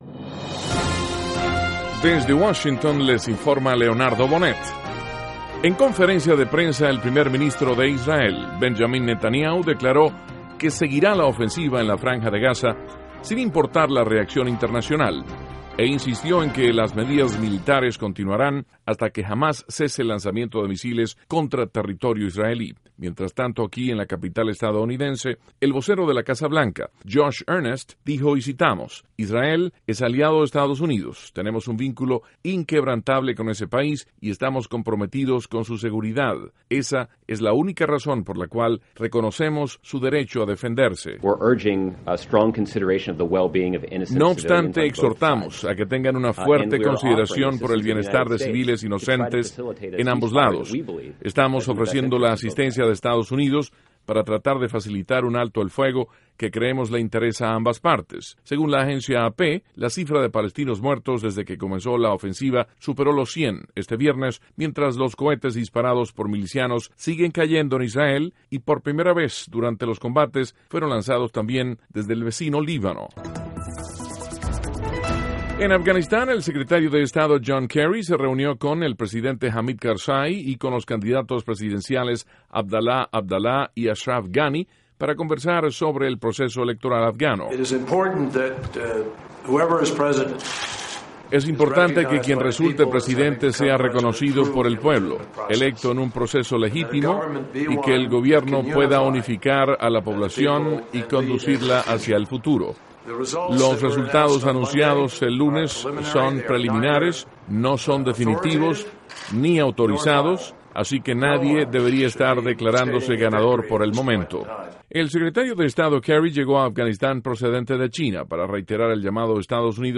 NOTICIAS - VIERNES, 11 DE JULIO, 2014